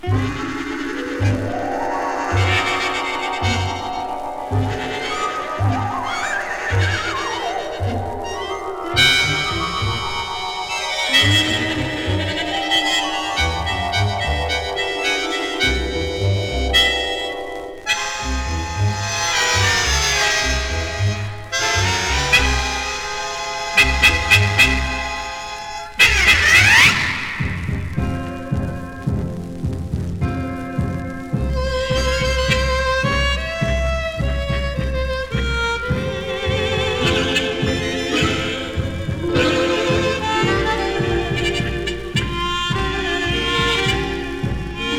ハーモニカ奏者
針をのせると、ふんわりと気持ちほどける夢見心地サウンドが実に気持ちよいのですが、実は只者じゃないレコード。
Jazz, Easy Listening, Strange　USA　12inchレコード　33rpm　Stereo